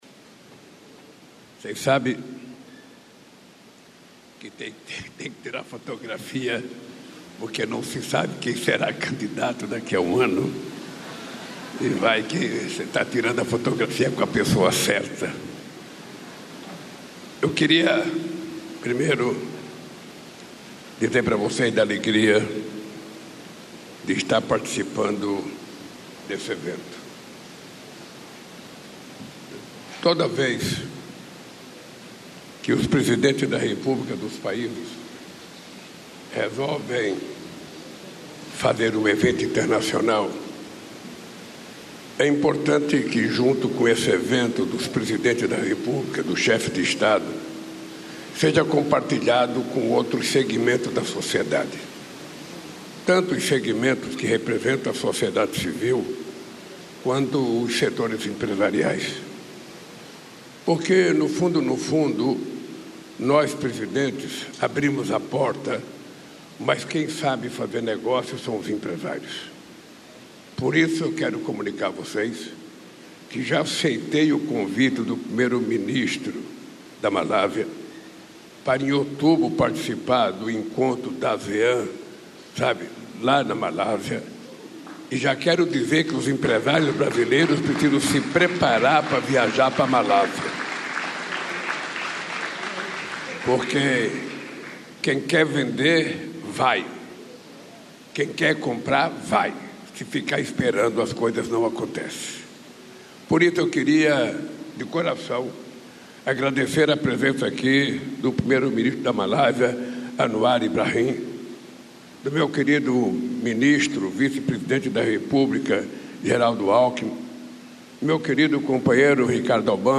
Íntegra do discurso do presidente Luiz Inácio Lula da Silva, na sessão plenária da Cúpula Brasil-Caribe, nesta sexta-feira (13), em Brasília.